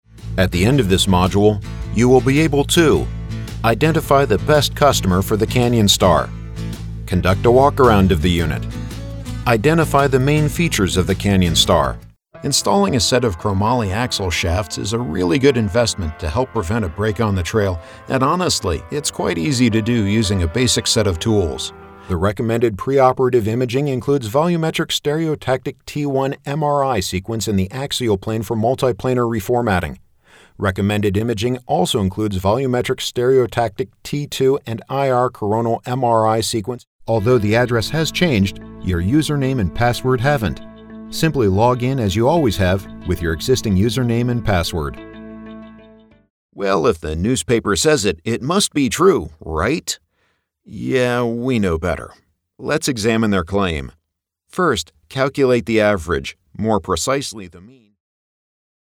US English; warm, authoritative, clear, kind, conversational, powerful, sincere; medical & technical eLearning; commercial; promo
Sprechprobe: eLearning (Muttersprache):
His vocal styles can reach many audiences; his gravelly voice is laden with gravitas and perfect for promos, while his lighter, fun voice over is ideal for conversational reads or witty copy that is so trendy today.